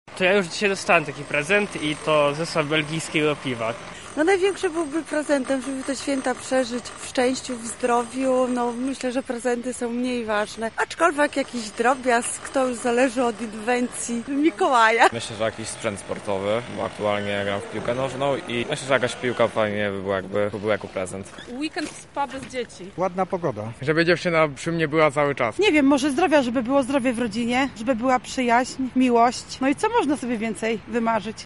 mikołajki sonda